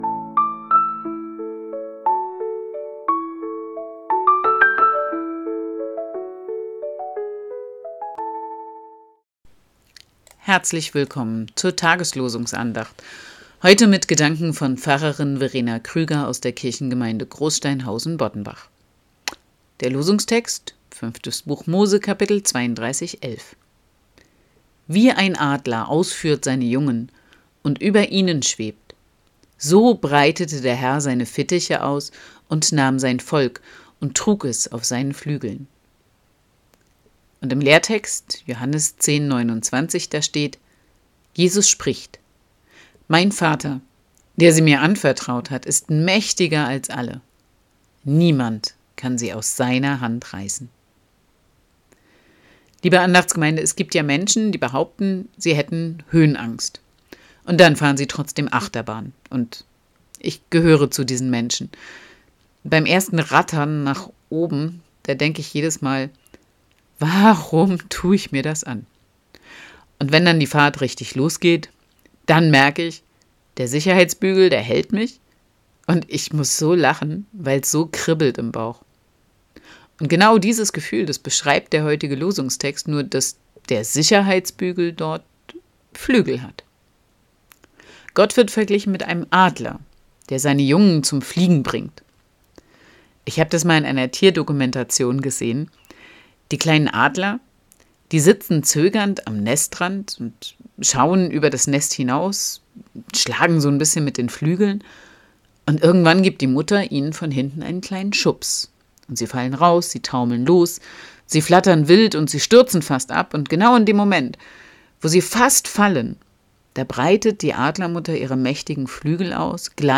Losungsandacht für Freitag, 14.11.2025
Text und Sprecherin: